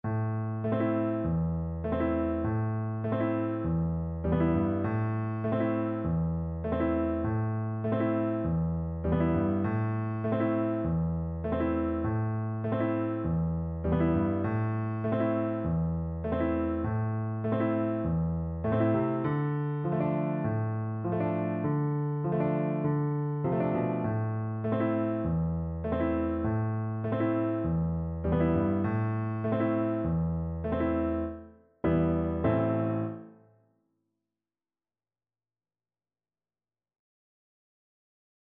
Play (or use space bar on your keyboard) Pause Music Playalong - Piano Accompaniment Playalong Band Accompaniment not yet available transpose reset tempo print settings full screen
Moderato
A minor (Sounding Pitch) E minor (French Horn in F) (View more A minor Music for French Horn )